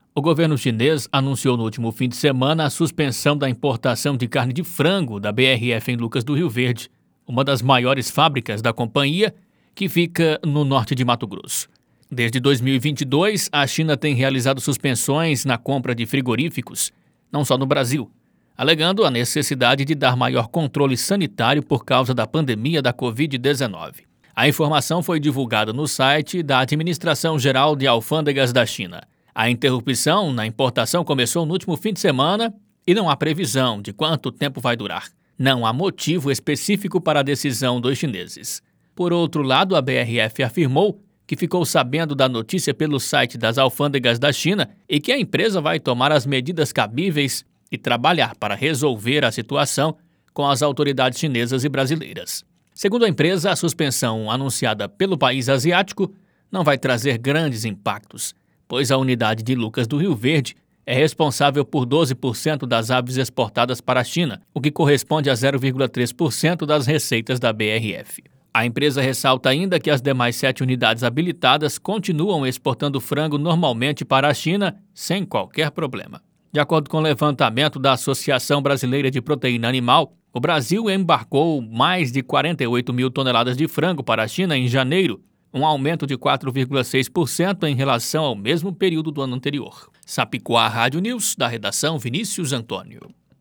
Sapicuá Comunicação – Assessoria e Produções em Áudio Radioagência Sápicuá de Notícias